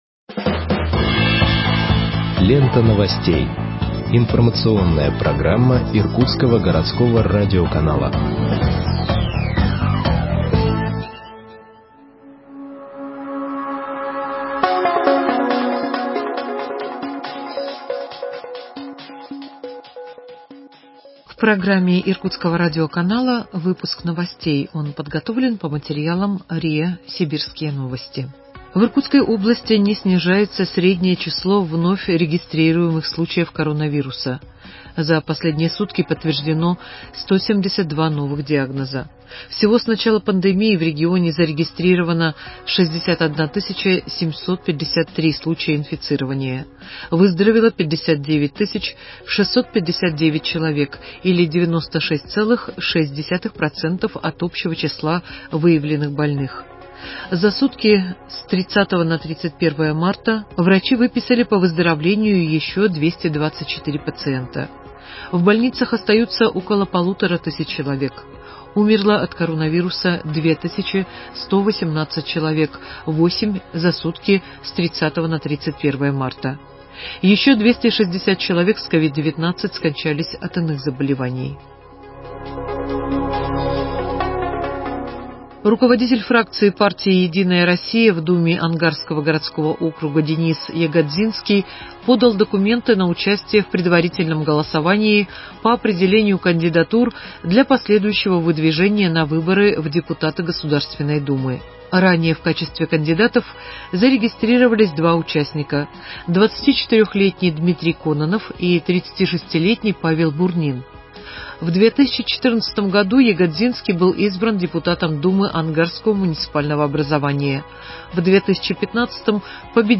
Выпуск новостей в подкастах газеты Иркутск от 01.04.2021 № 1